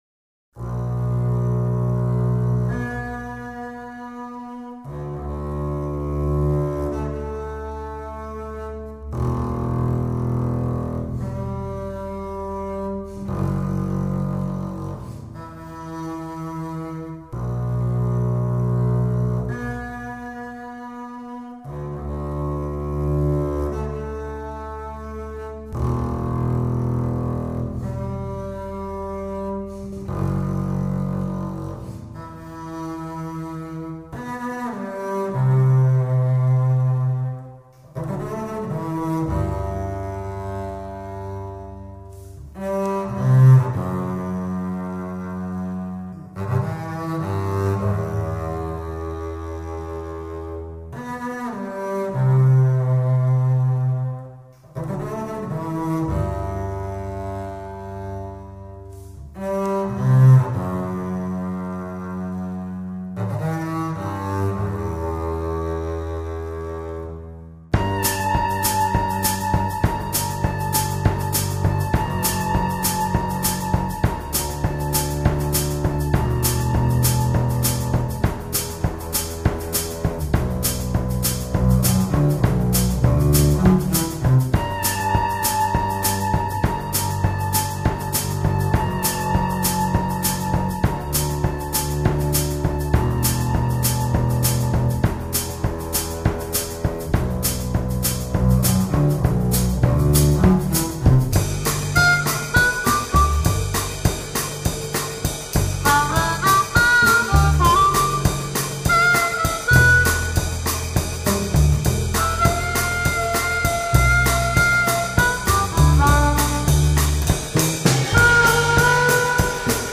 Written for Harmonica, Bass and Drum Kit